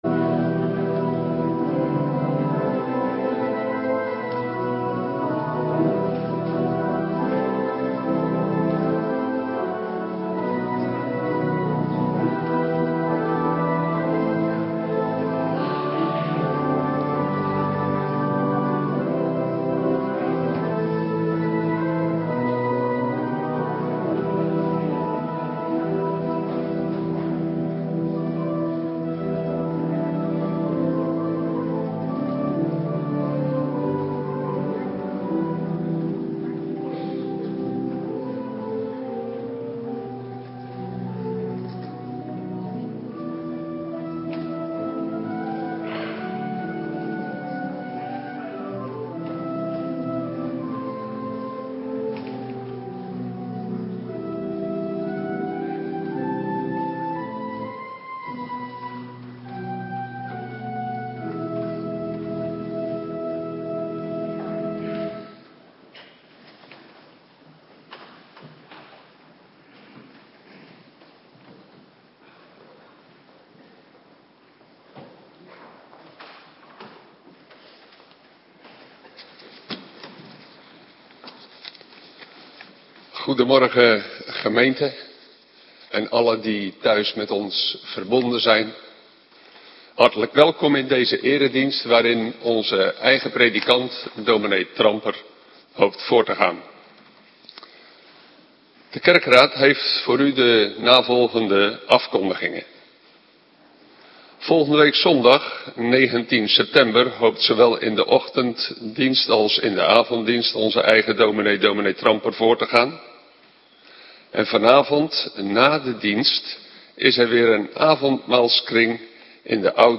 Morgendienst Heilig Avondmaal
Locatie: Hervormde Gemeente Waarder